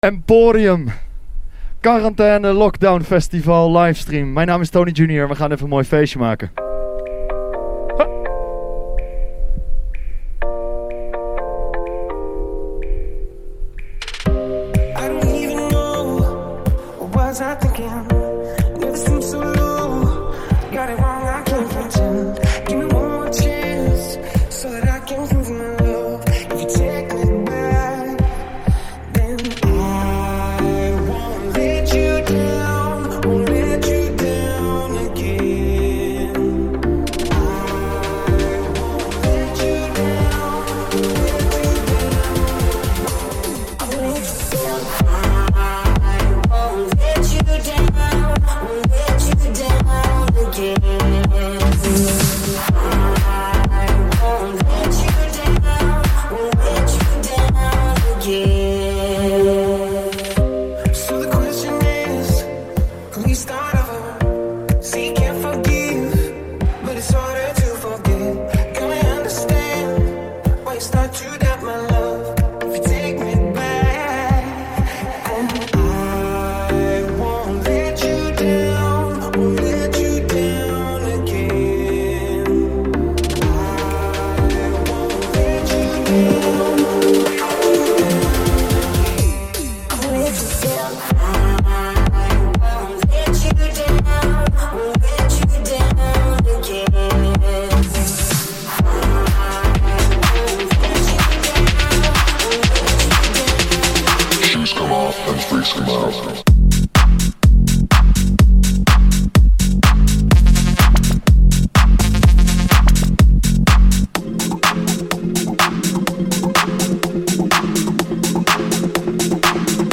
Genre: House